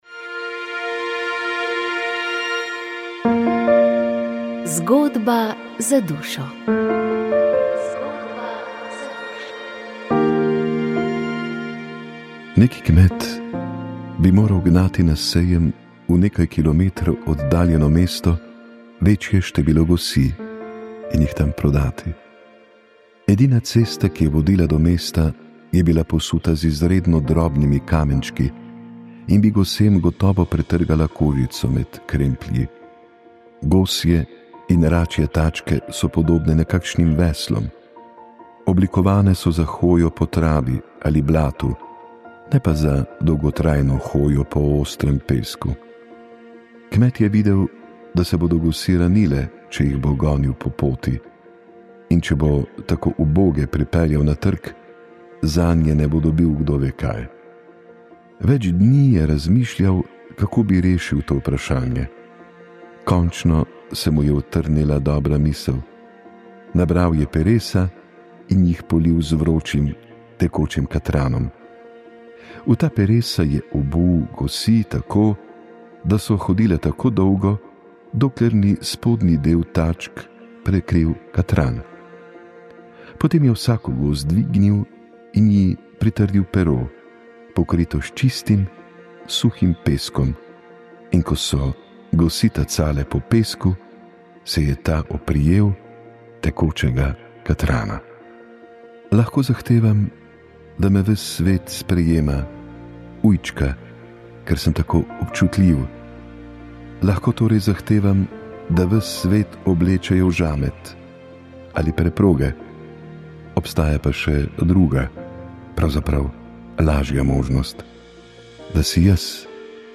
Knjiga, ki obravnava slovenske vojake v vojskah 20. stoletja, s poudarkom na nastanku oboroženih sil demokratične slovenske države, je leta 2021 izšla pri založbi Nova obzorja. Posnetek predstavitve lahko poslušate v tokratni oddaji Moja zgodba.